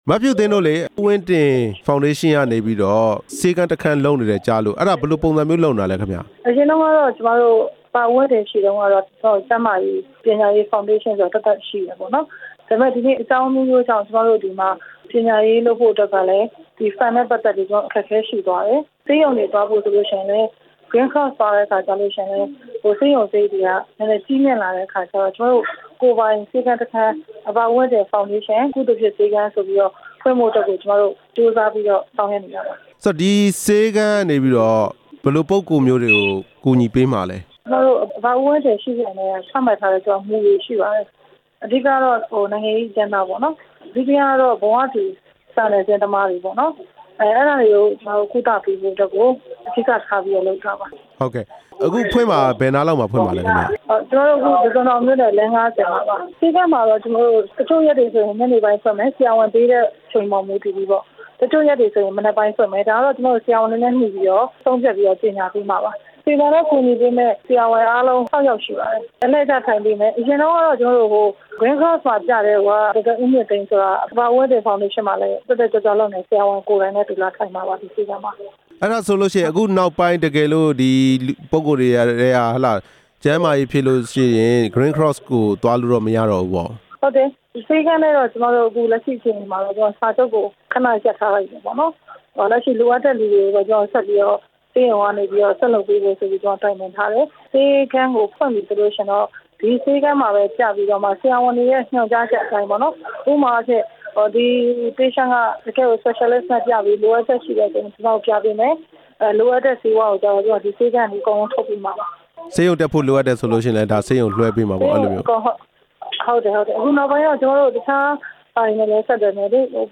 ဟံသာဝတီ ဦးဝင်းတင် ဖေါင်ဒေးရှင်းကော်မတီဝင် ဒေါ်ဖြူဖြူသင်းနဲ့ မေးမြန်းချက်